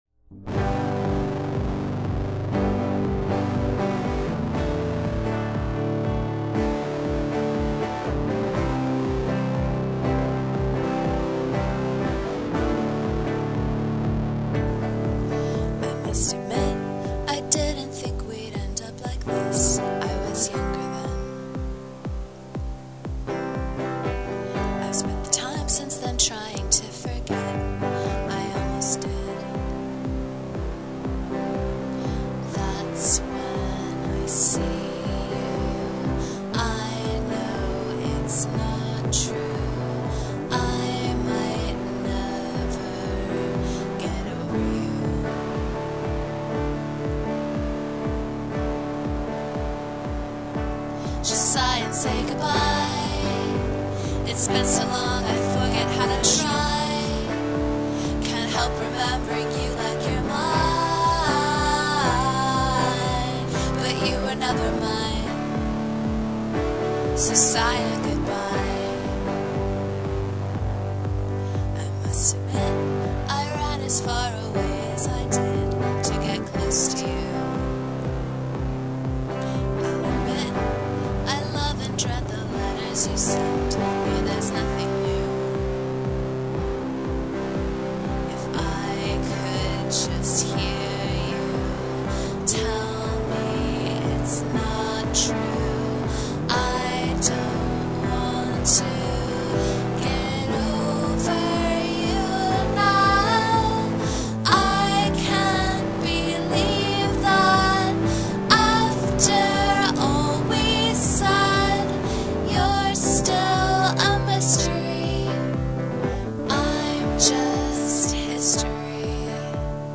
a-b-c-a-b-c->d-a-a
key of g (i think?)
there are four guitar tracks, two clean and two big muffed, all recorded direct into the mixer, and two tracks of each vocal part, recorded with an incredibly shitty mic and a little too much gin.
written, musically.  it's just really purposeful and well-paced.  the melodies play a huge part in those dynamic